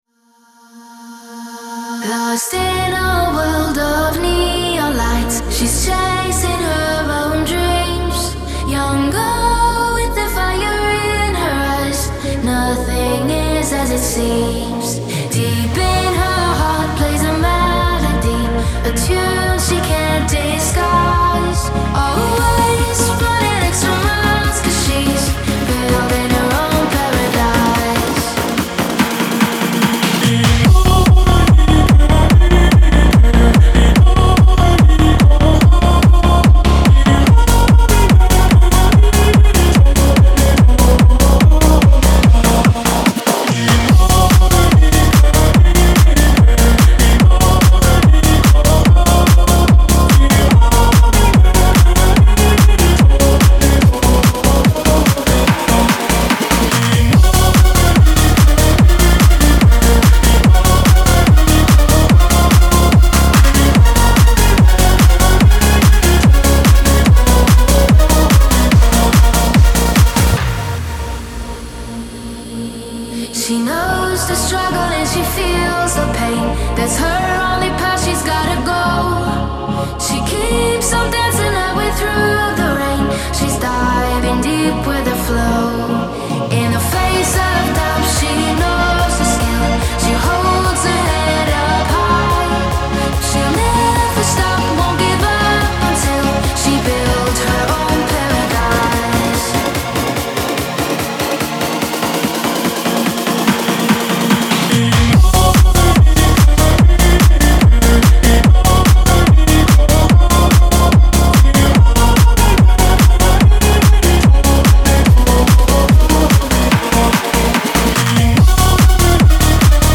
• Жанр: Electronic, Dance